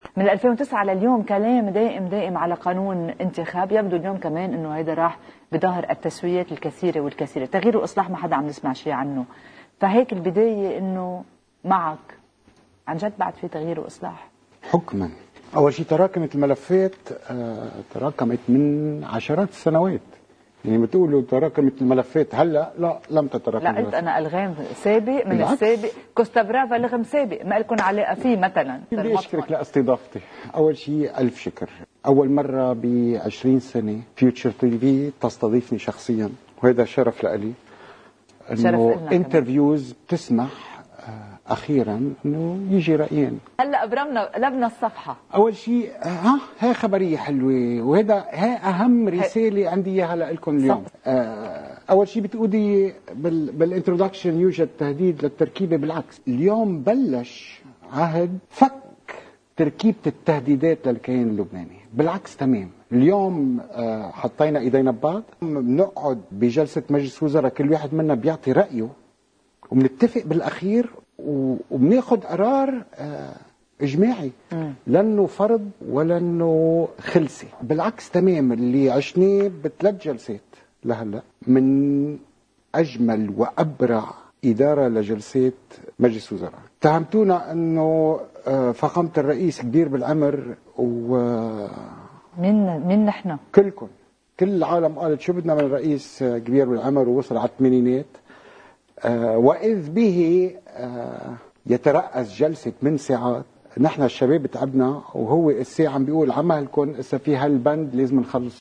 مقتطف من حديث وزير الدفاع يعقوب الصراف على قناة “المستقبل” مع الإعلامية بولا يعقوبيان: